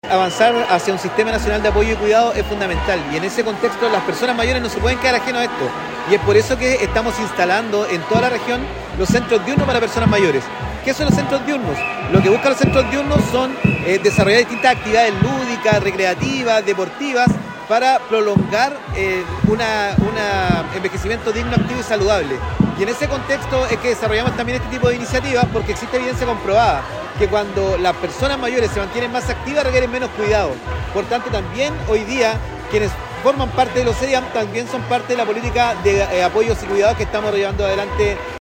En ese sentido, el seremi de Desarrollo Social y Familia, Hedson Díaz, comentó que “esta fiesta es un claro ejemplo de que la participación activa de las personas mayores es clave en un pasar mucho más entretenido y saludable, hemos visto una competencia de mucha calidad y lo más importante, hemos visto en cada cara una sonrisa y una alegría que contagia”.
cuna-01-olimpiadas-adultos-mayores.mp3